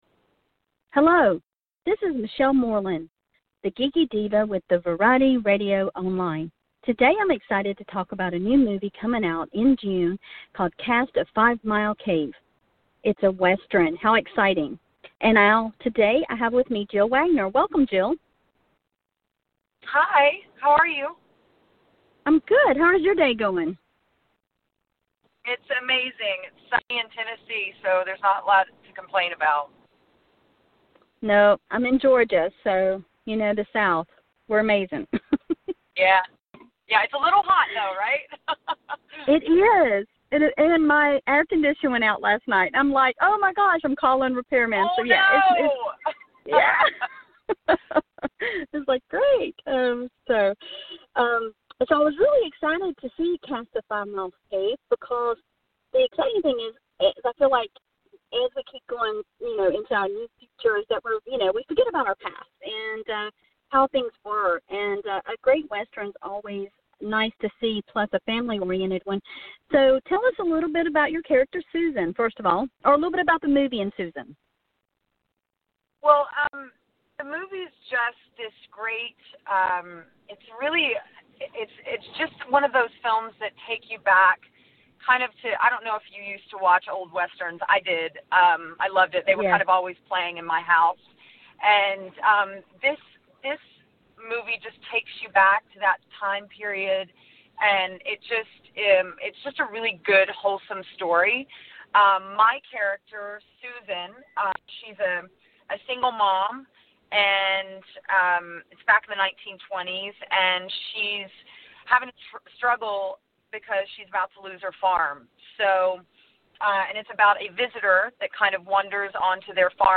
Jill Wagner calls into Variety Radio Online to talk about her upcoming movie The Legend of 5 Mile Cave, which will premiere on INSP on June 9th at 8PM ET.